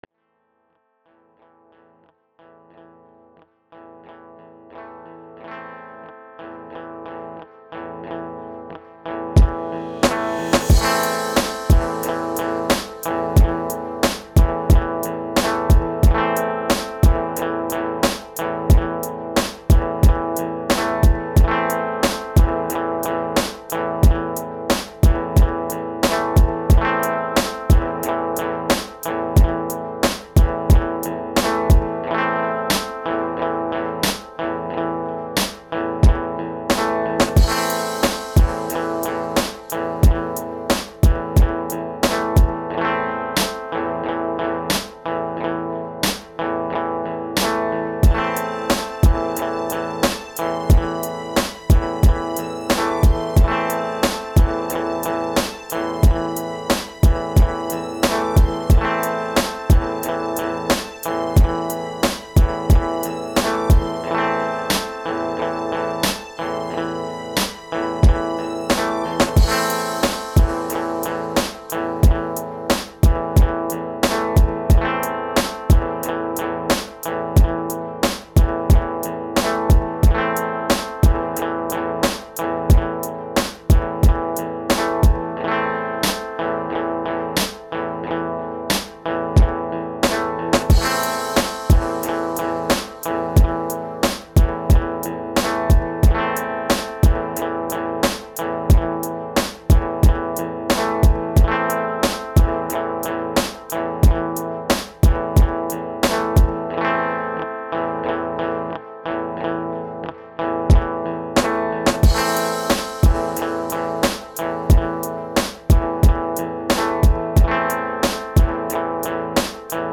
Стиль: Hip-Hop